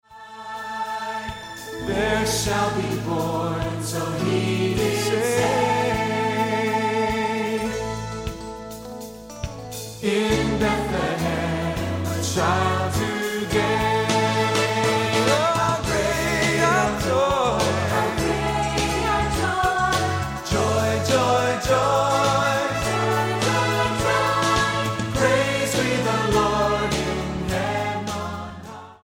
STYLE: Orchestral